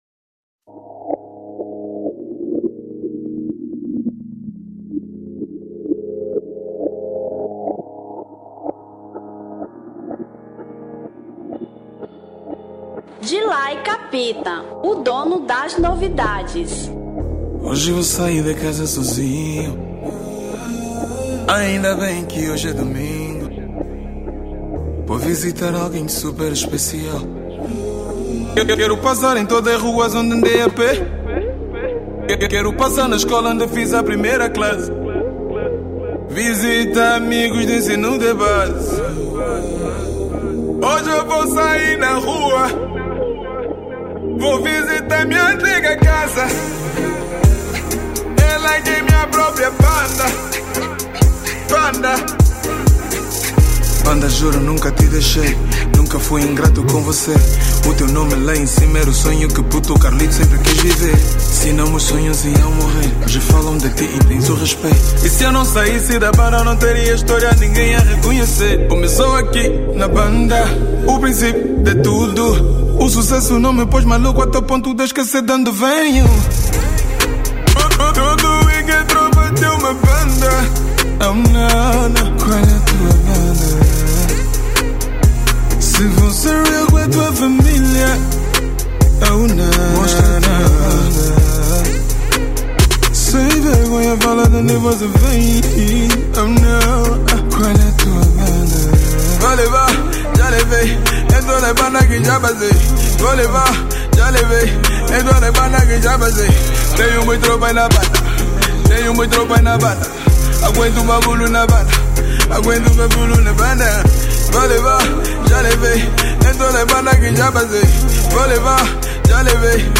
Afro Pop 2017